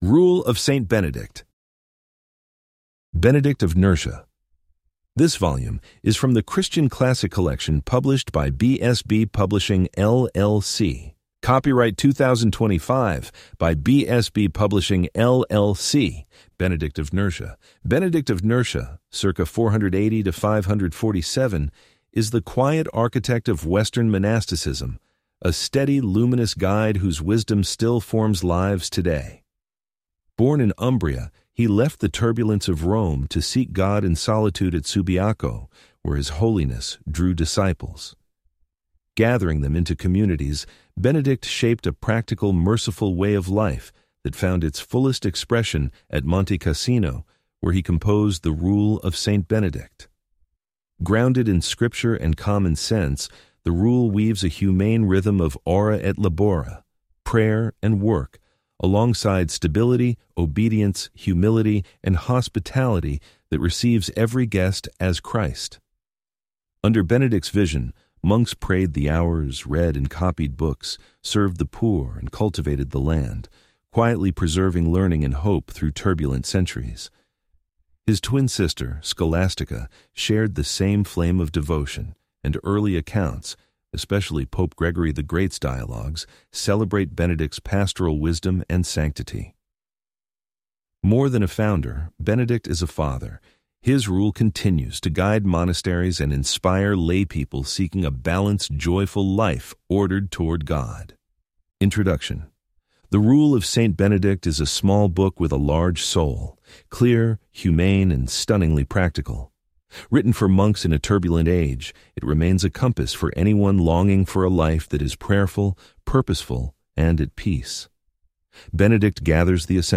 Complete Audiobook Play Download Individual Sections Listening Tips Download the MP3 files and play them using the default audio player on your phone or computer.